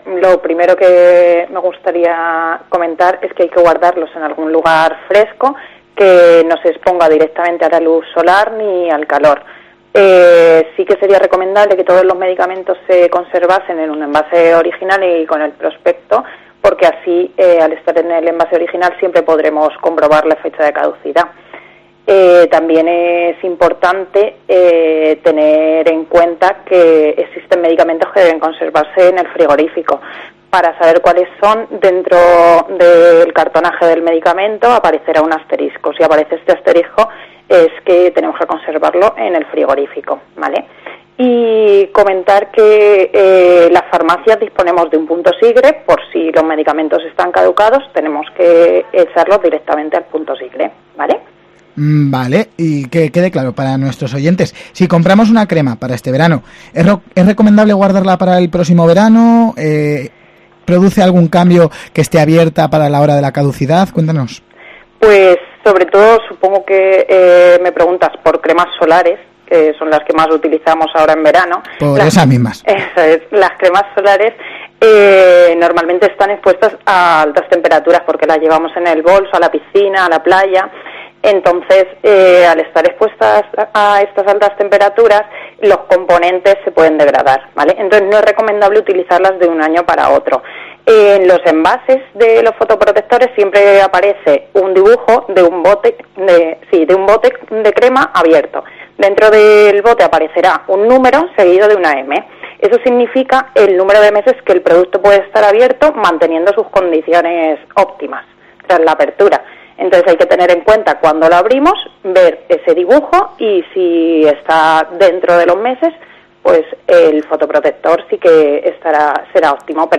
ENTREVISTA | ¿Sabes cómo puedes mantener los medicamentos y/o cremas?